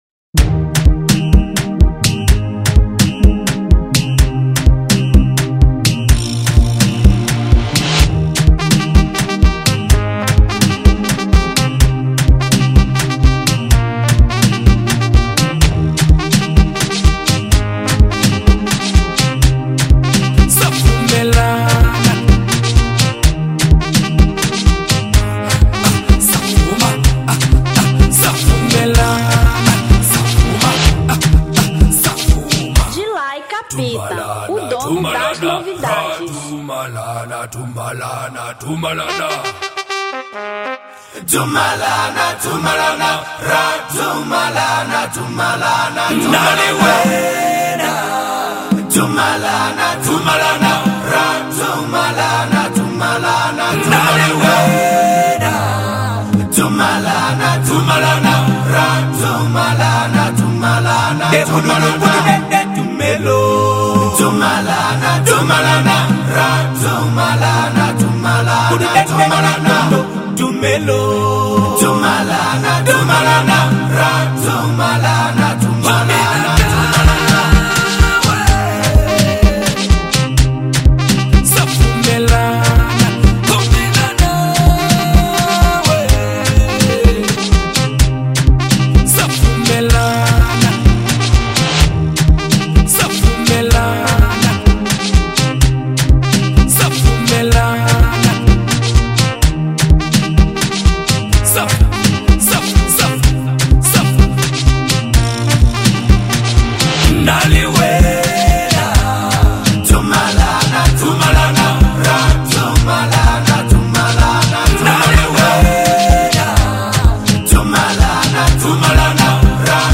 Amapiano 2019